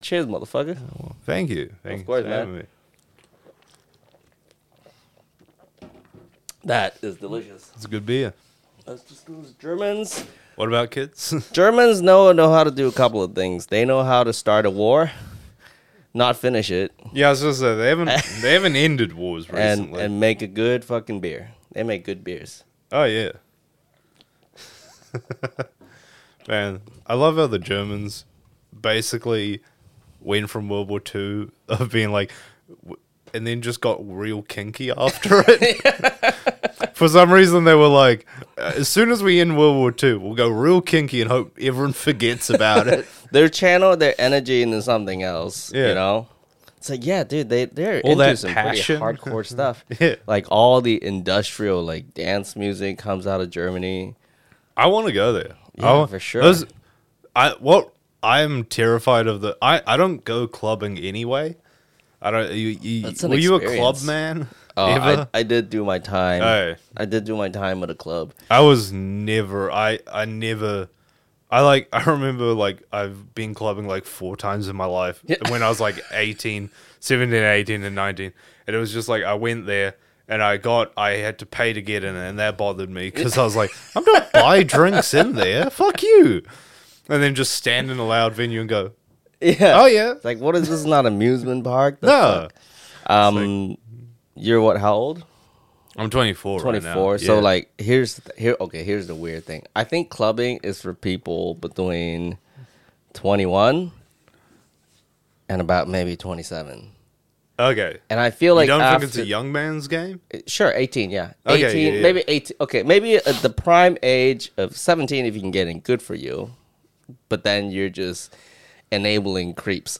Who Wants the Last Bite? is a comedy podcast for a casual, fun time where food meets culture.